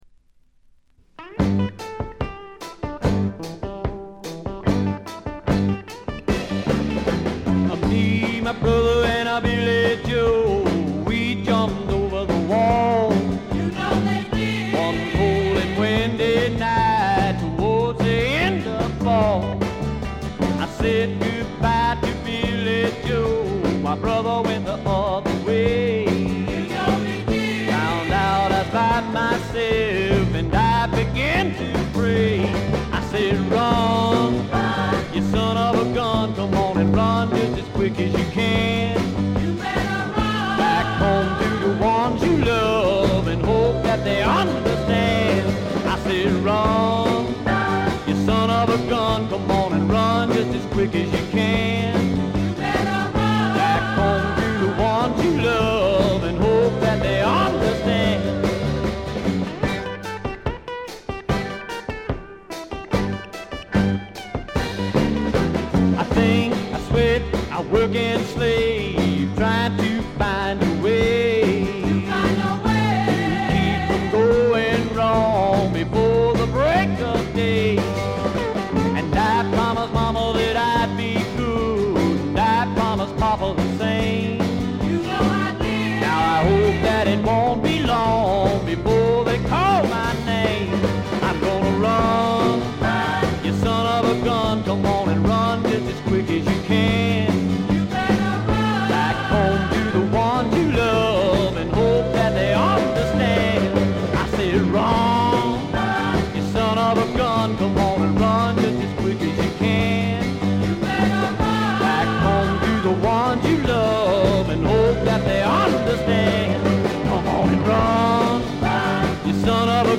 ごくわずかなノイズ感のみ。
謎のシンガー・ソングライター好盤です。
時代を反映してか、ほのかに香る土の匂いがとてもいい感じですね。
試聴曲は現品からの取り込み音源です。
Engineered At - Gold Star Studios